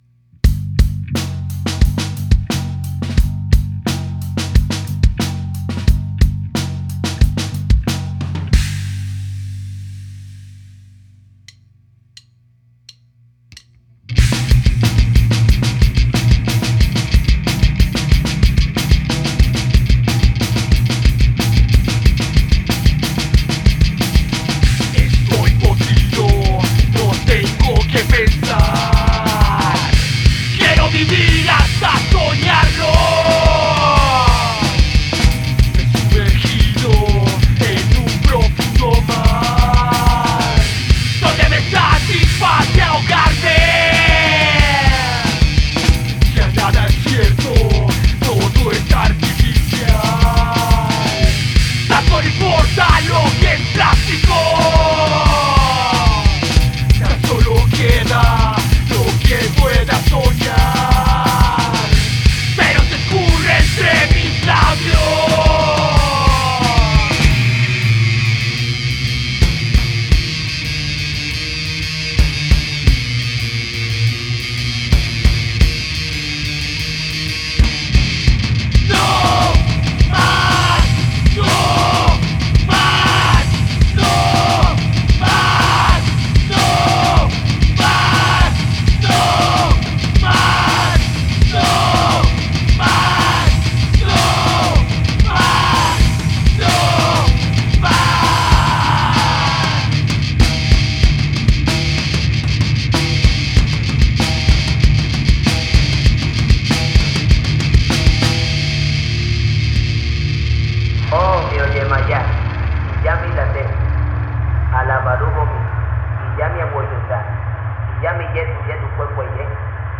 De carácter introspectivo y conceptual
Rock experimental